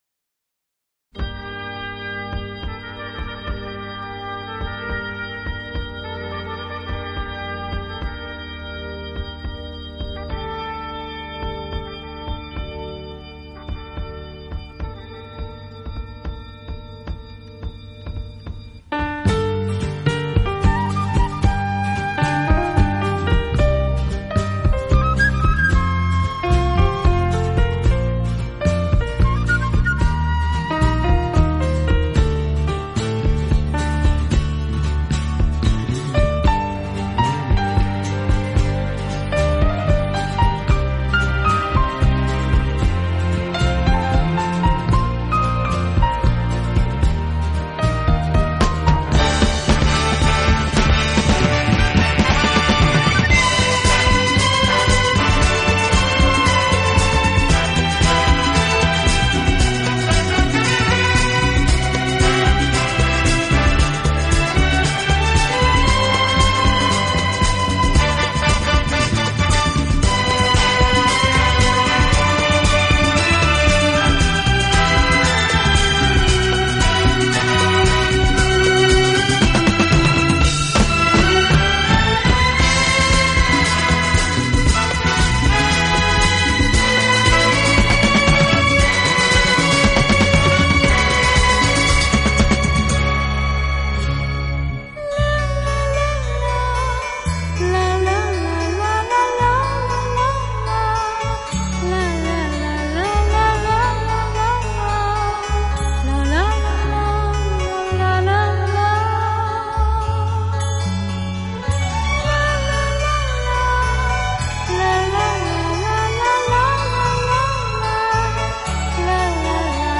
因其优美飘逸的旋律名扬全球